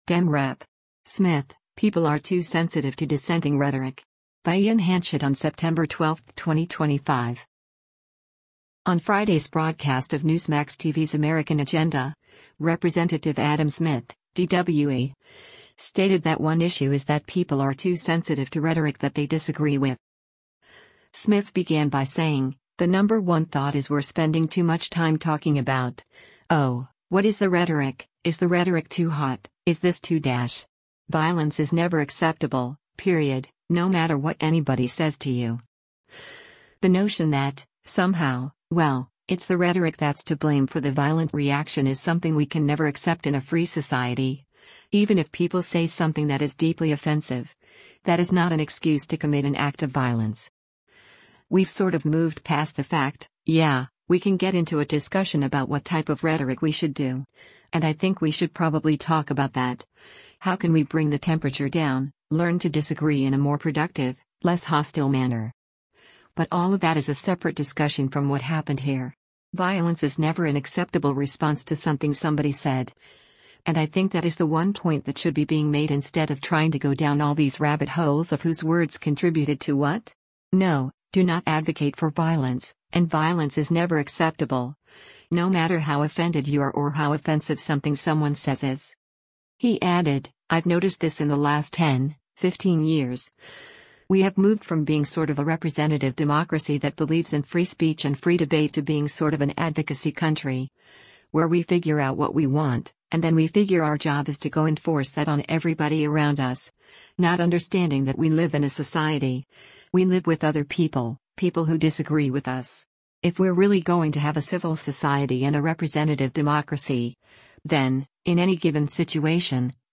On Friday’s broadcast of Newsmax TV’s “American Agenda,” Rep. Adam Smith (D-WA) stated that one issue is that people are too sensitive to rhetoric that they disagree with.